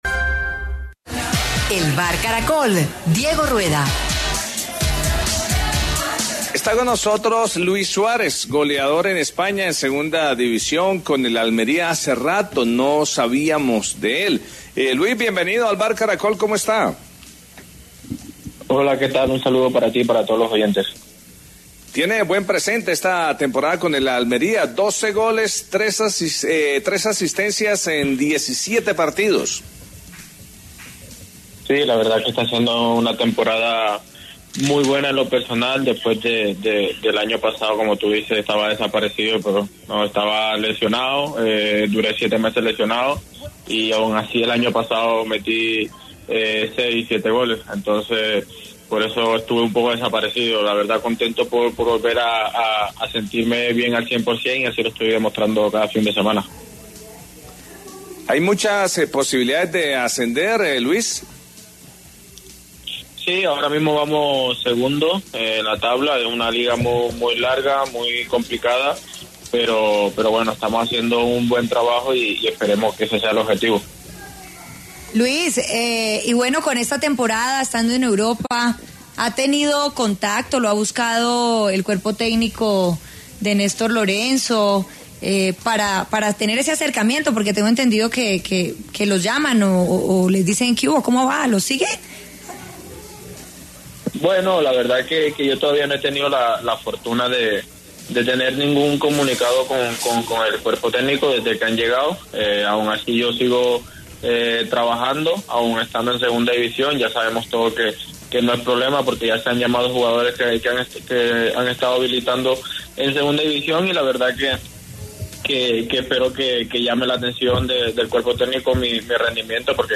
Luis Javier Suárez, delantero colombiano del Almería de la segunda división de España, dialogó con El VBar de Caracol Radio.